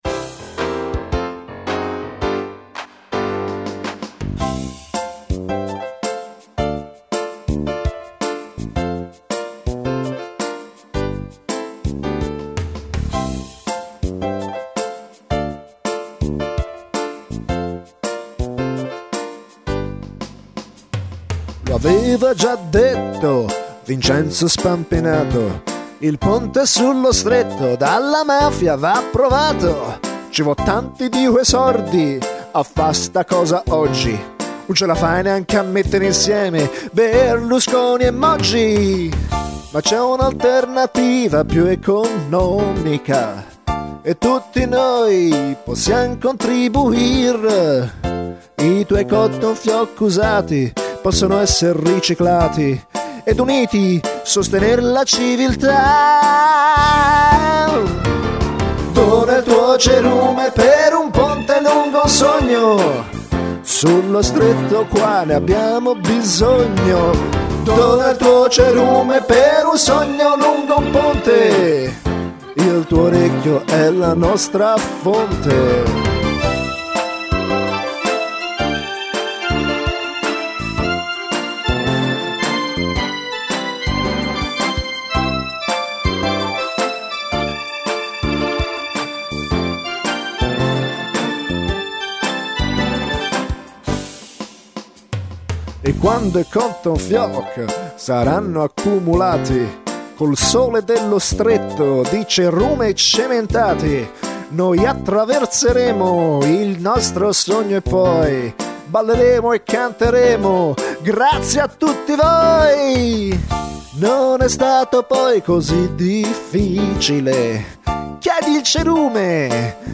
Che blues, che sound, che ridere!